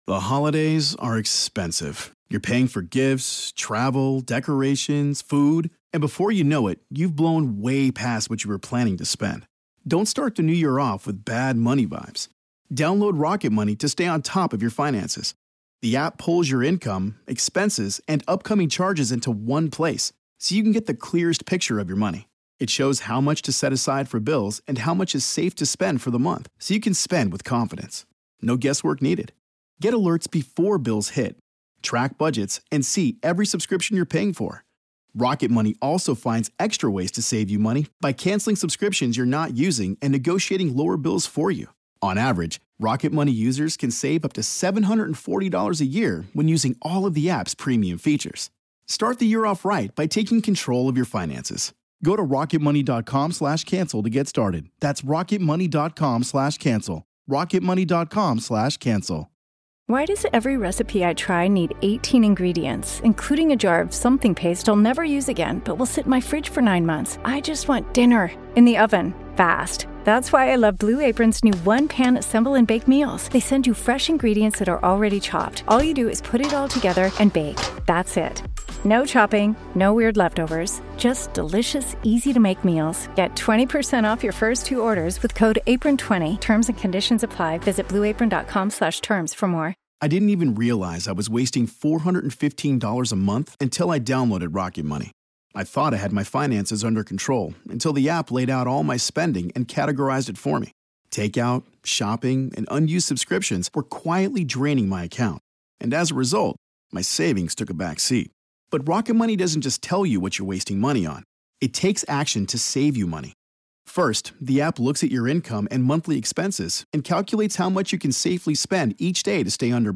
talks with comedians, actors, and filmmakers about horror movies!